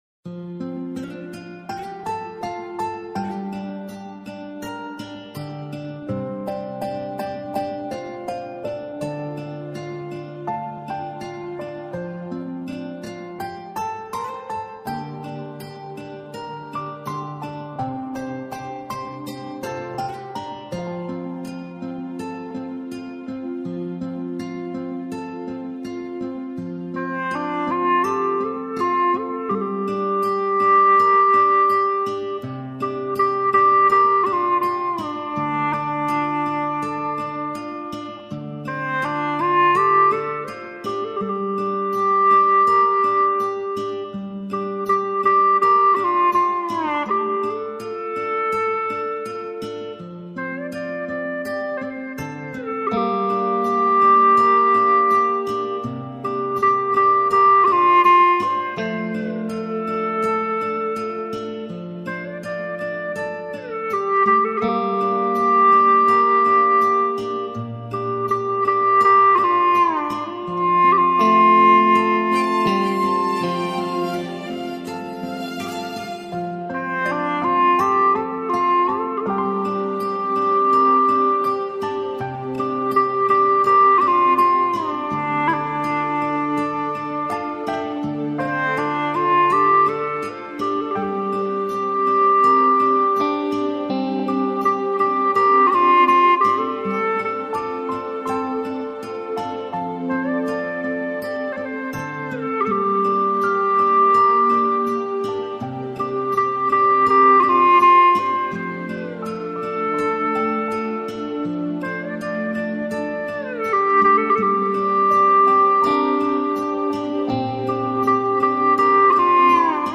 调式 : F